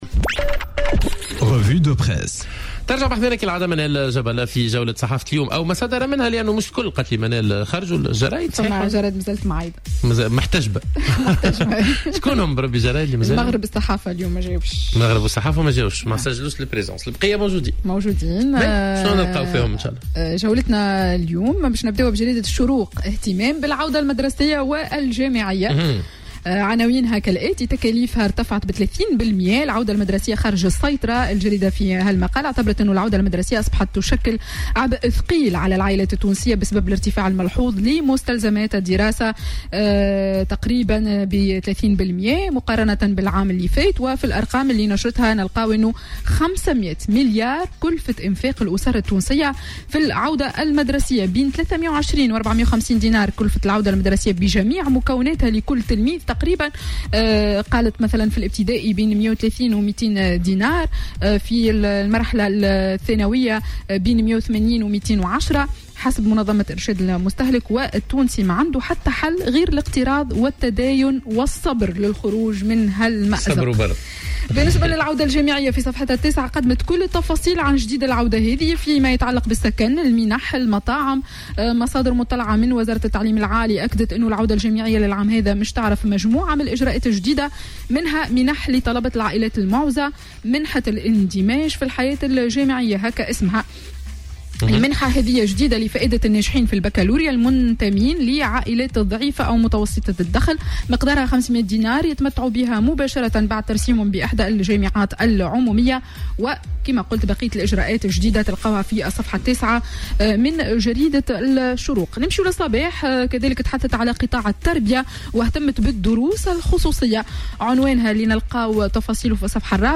معرض الصحافة ليوم الخميس 23 أوت 2018